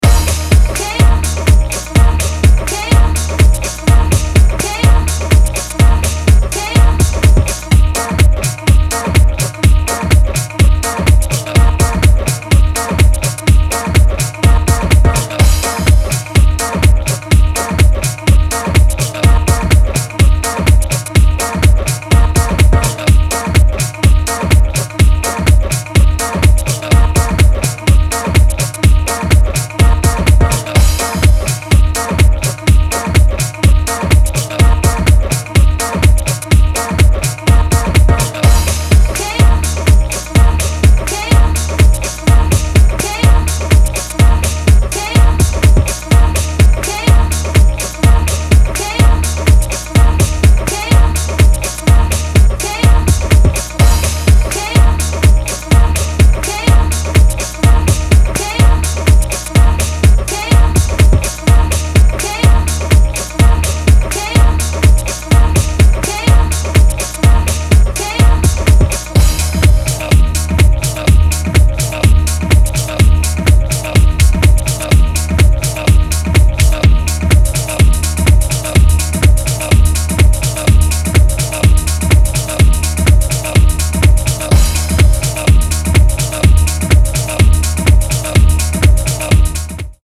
ビルドアップしていくパーカッシヴなピークタイム・チューン